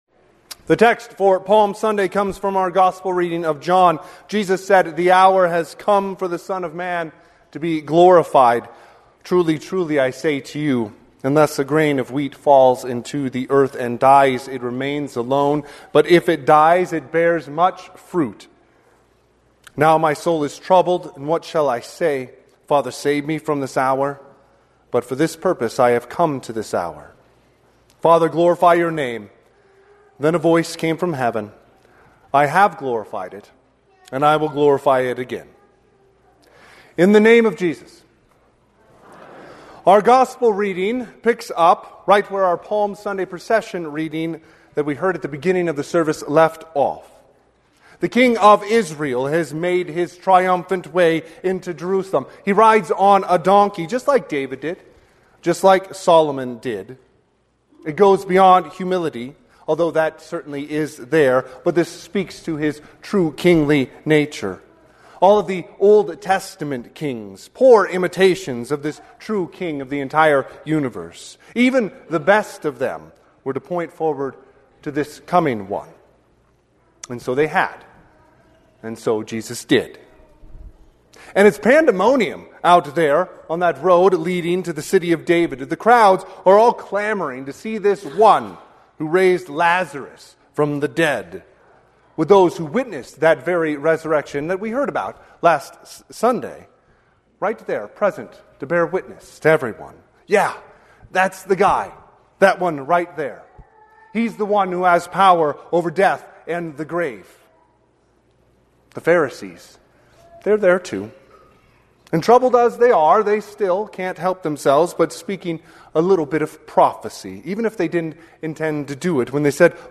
Sermon – 3/29/2026 - Wheat Ridge Evangelical Lutheran Church, Wheat Ridge, Colorado